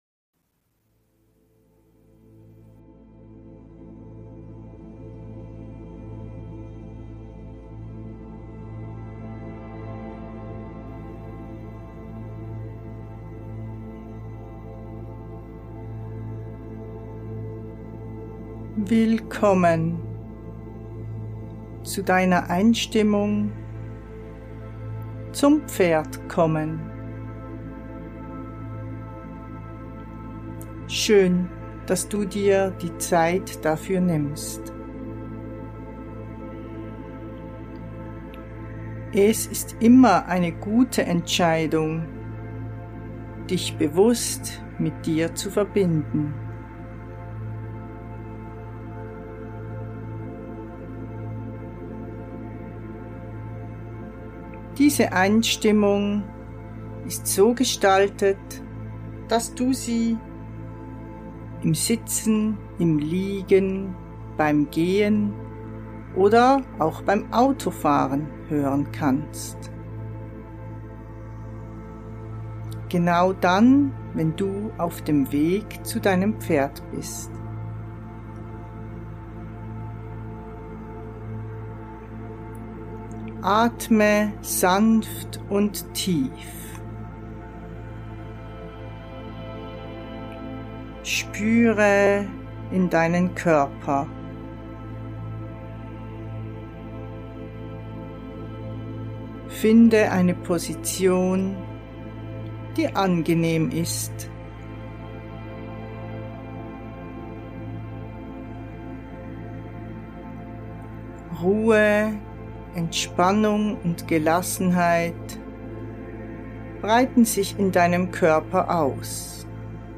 Es handelt sich um eine Hypnobreath-Meditation. Hypnobreath-Meditation ist eine besondere Art der Meditation, die mit verbundenem Atem und Atempausen arbeitet.
Für deine Bequemlichkeit habe ich in dieser Episode die längeren Atempausen weggelassen und einen recht flotten Atemrhythmus gewählt.